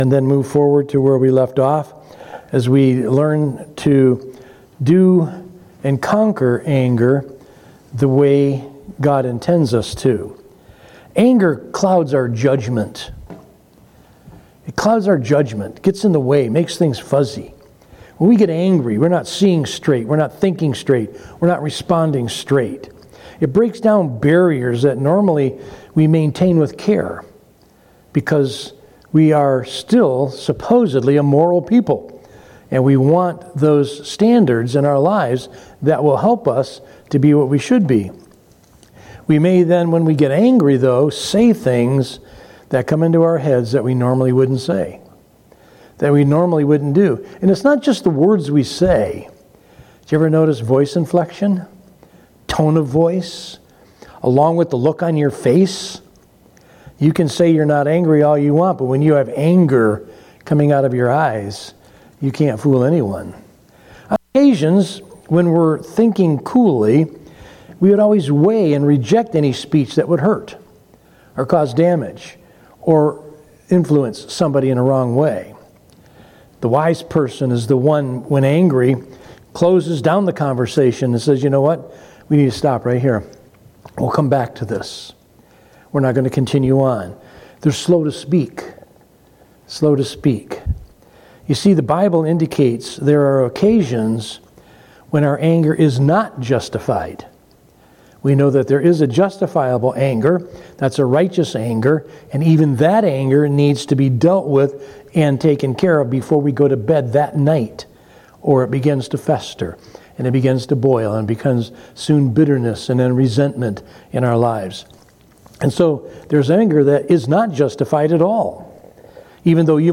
From Series: "Sunday Morning - 11:00"
Sermon